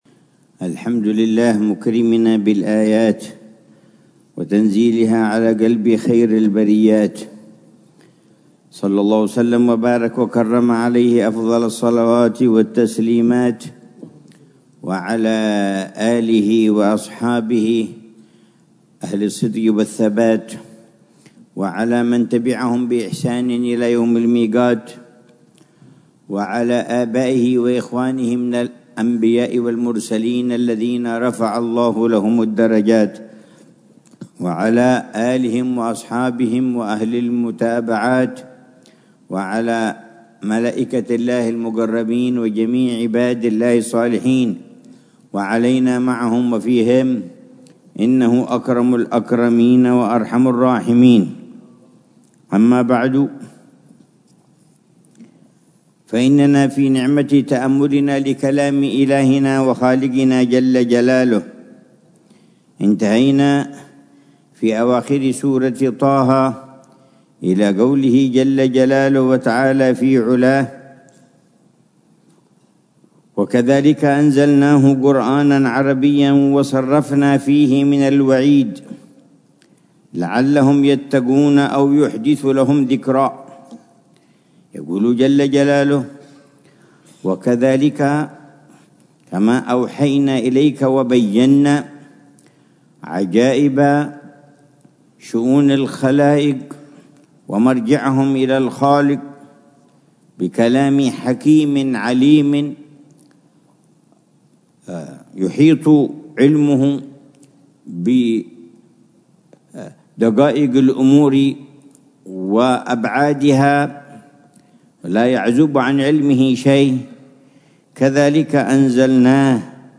تفسير فضيلة العلامة الحبيب عمر بن محمد بن حفيظ للآيات الكريمة من سورة طه